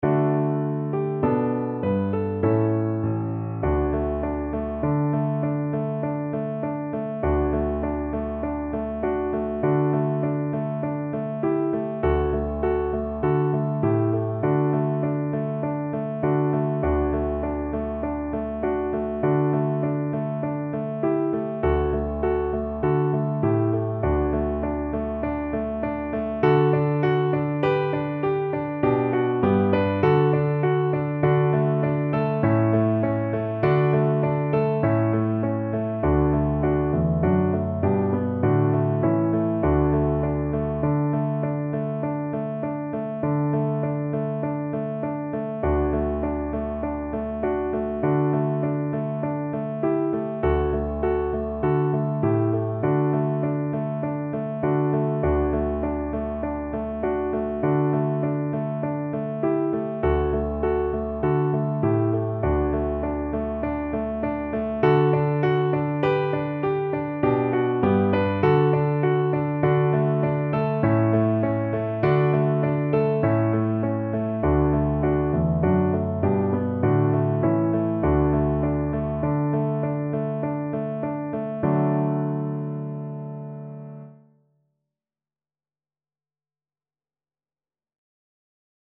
D major (Sounding Pitch) (View more D major Music for Cello )
Moderato
4/4 (View more 4/4 Music)
Traditional (View more Traditional Cello Music)